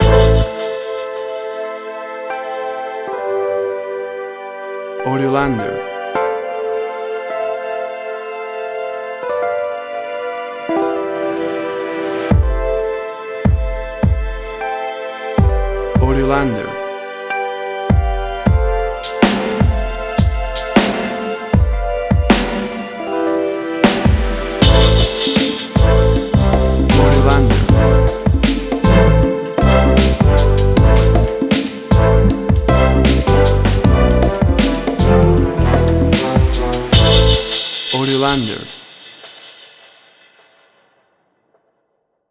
Tempo (BPM): 78